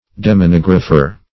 demonographer - definition of demonographer - synonyms, pronunciation, spelling from Free Dictionary
Search Result for " demonographer" : The Collaborative International Dictionary of English v.0.48: Demonographer \De`mon*og"ra*pher\, n. [Demon + -graph + -er.]